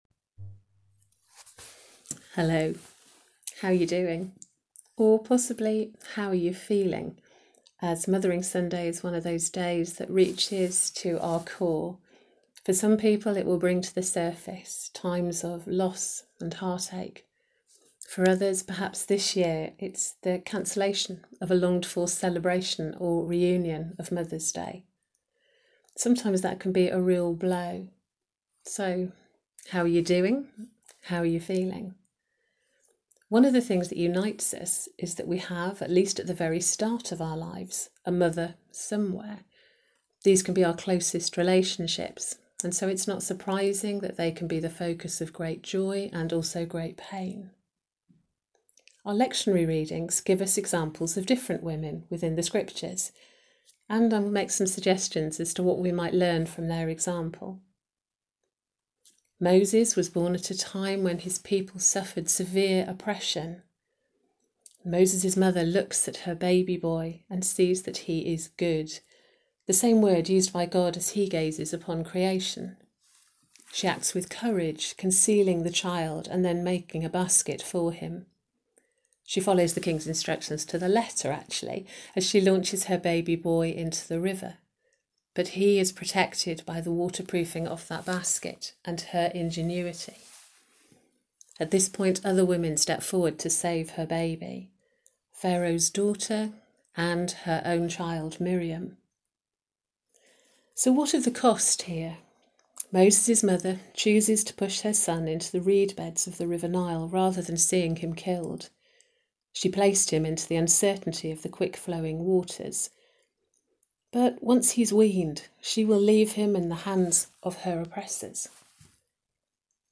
Sermon on Mothering Sunday